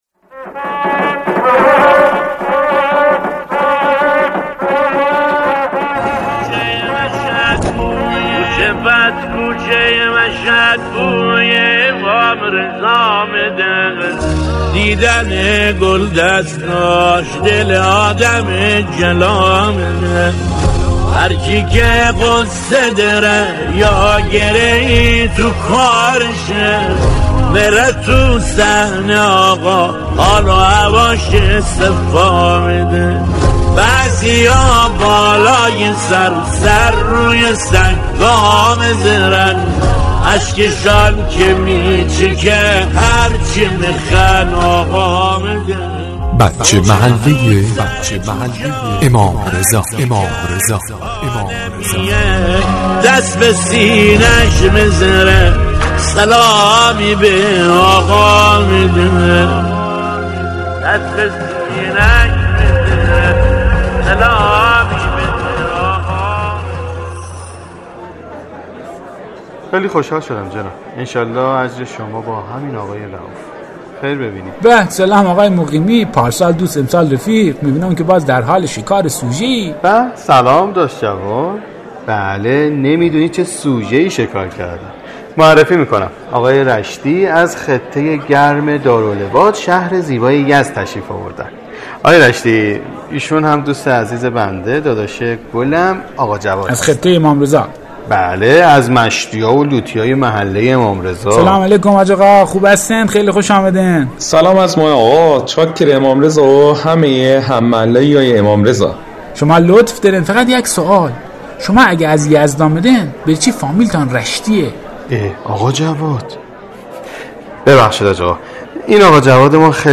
نمایش رادیویی بچه محل امام رضا (ع) قسمت چهارم؛